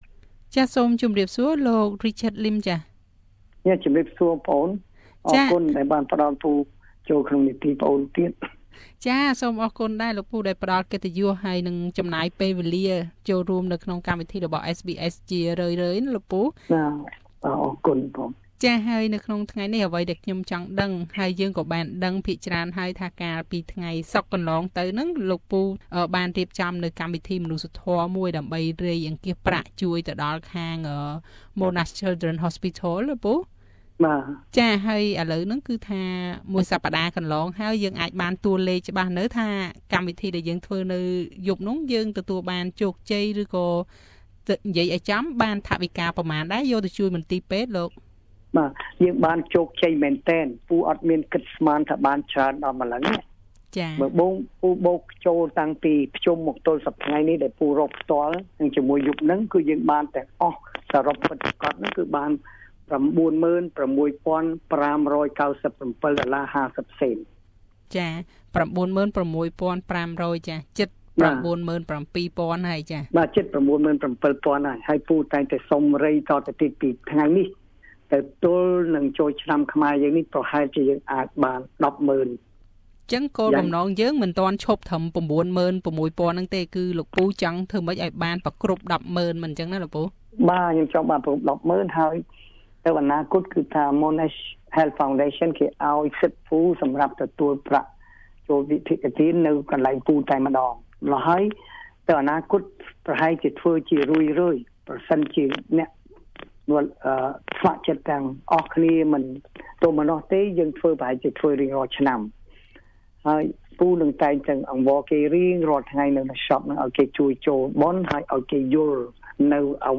តើប្រាក់ទាំងនេះបានមកពីណាខ្លះ? សូមស្តាប់បទសម្ភាសន៍ដូចតទៅ។